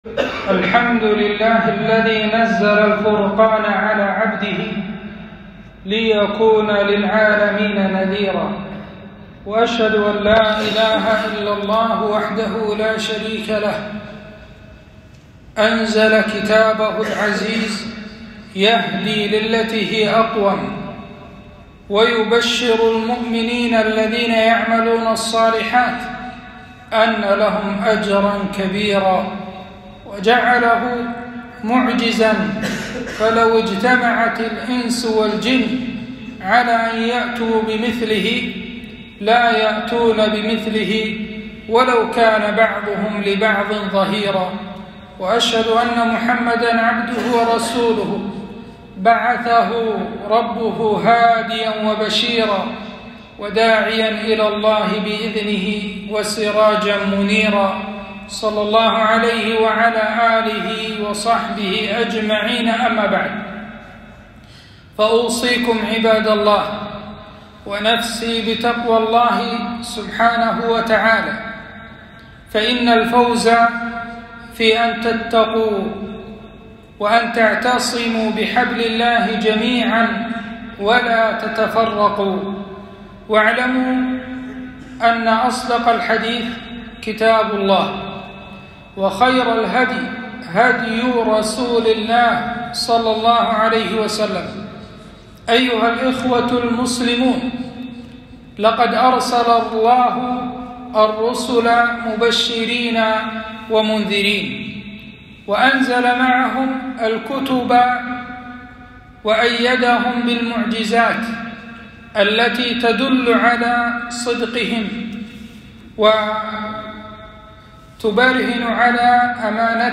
خطبة - إنا نحن نزلنا الذكر وإنا له لحافظون